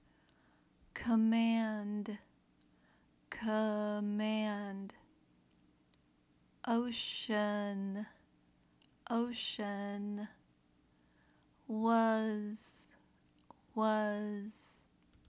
Review the Schwa Sound
In this lesson, there are three words with the schwa sound.
Practice the Schwa Sound